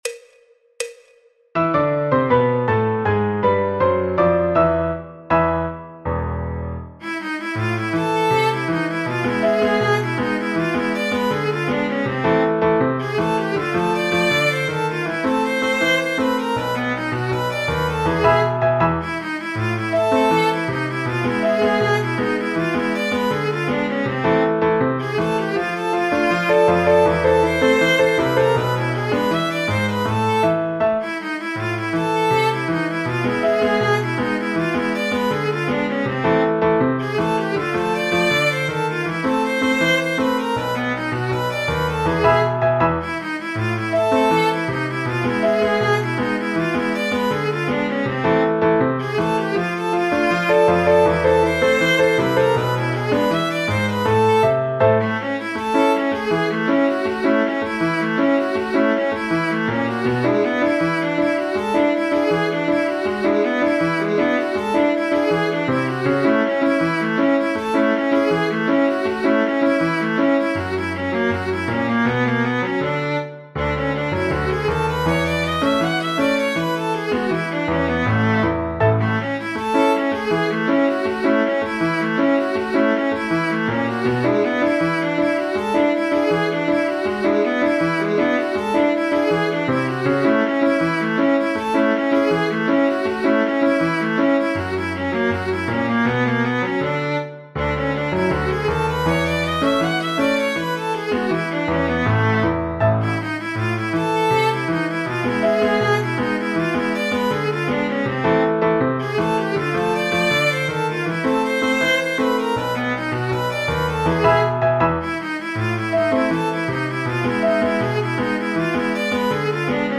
Choro, Jazz, Popular/Tradicional